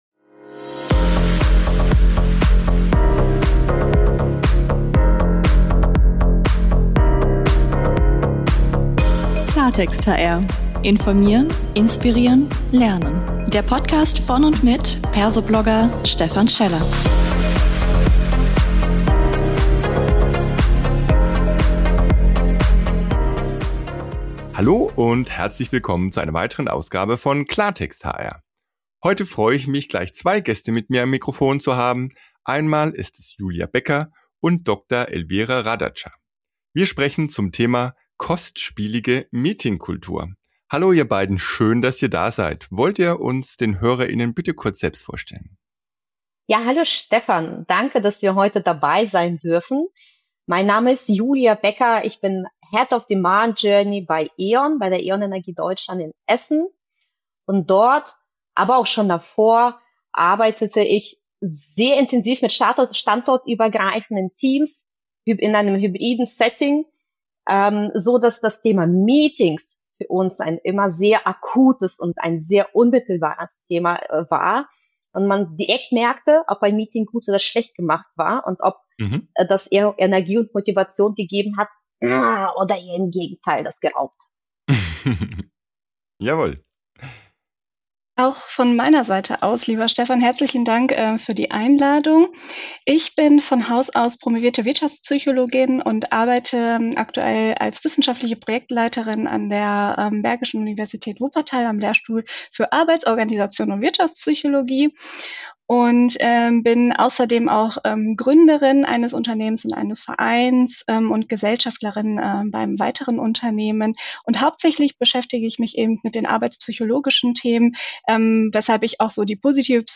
Ein spannender Talk als 20-Minuten-Impuls.